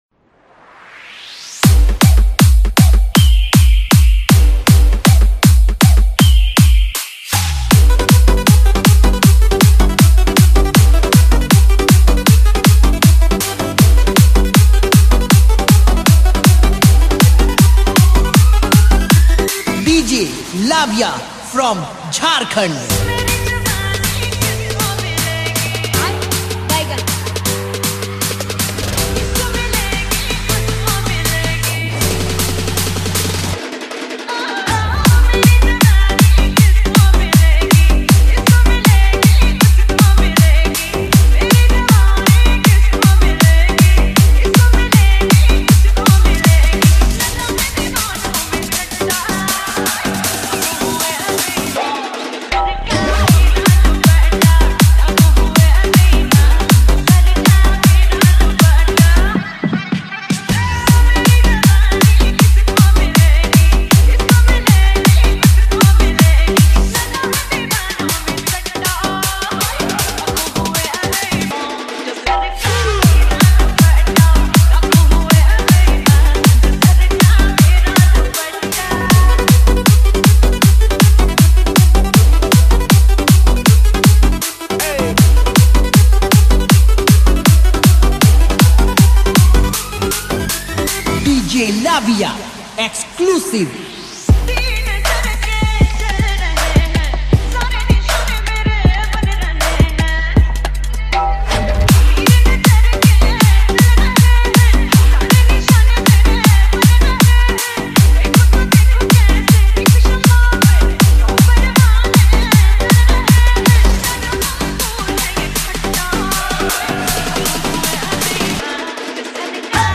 Category : Old Is Gold Remix Song